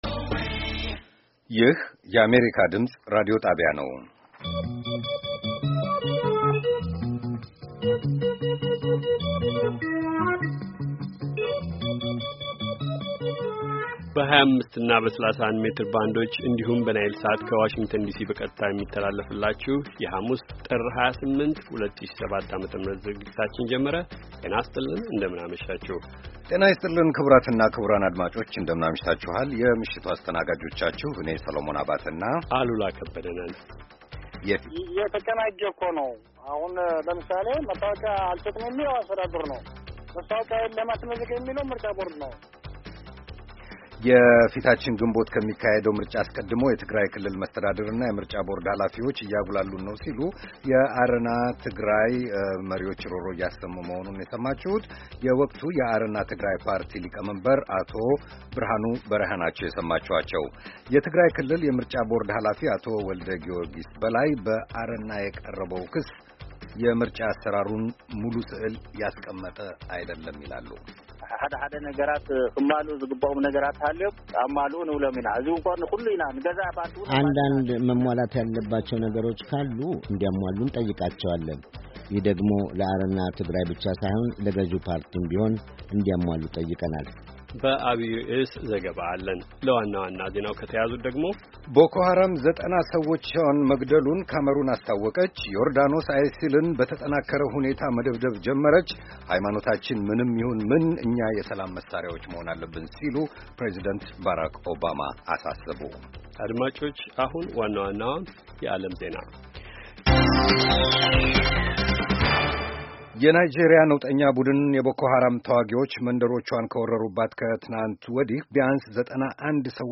ከምሽቱ ሦስት ሰዓት የአማርኛ ዜና